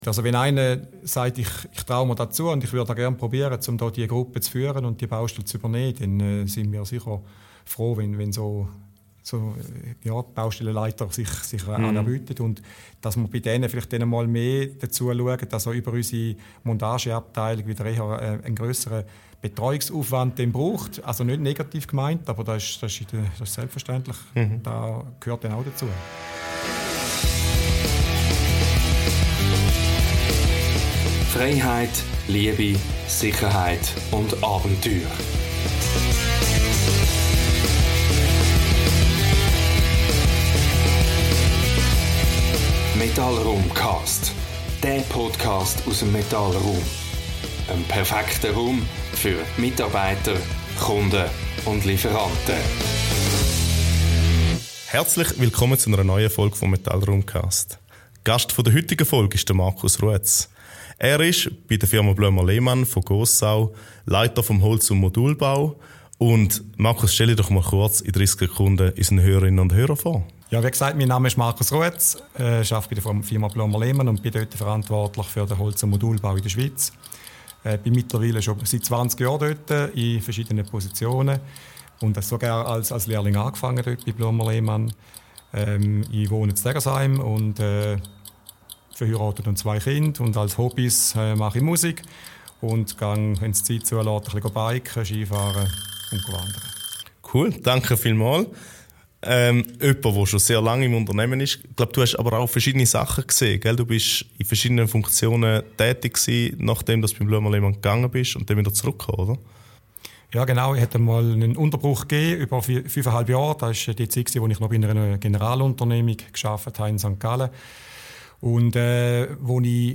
Im Gespräch geht es darum, wie sich traditionelle Handwerkskunst mit modernster Technologie vereinen lässt, welche Verantwortung Unternehmen heute für Ressourcen und Klima tragen – und warum Mut zur Veränderung der wichtigste Rohstoff der Zukunft ist. Ein inspirierender Austausch über Haltung, Innovation und die Kraft des Holzes.